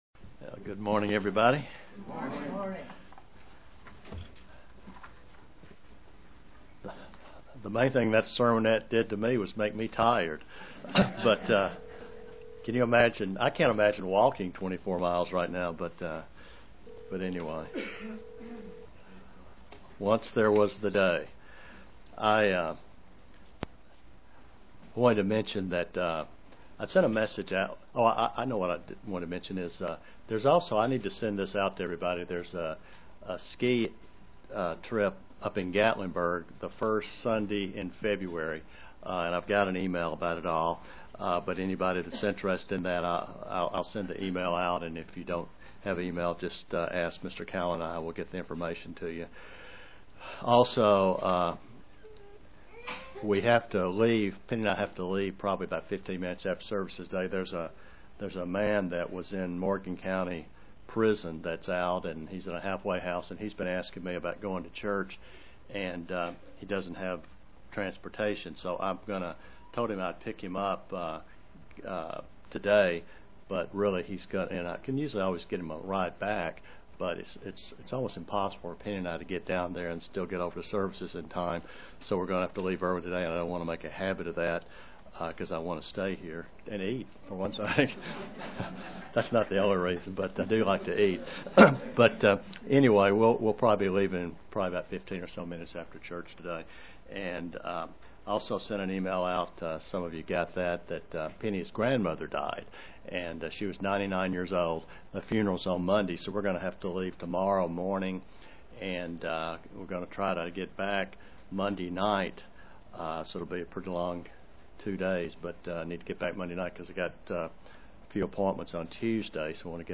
Given in London, KY
UCG Sermon Studying the bible?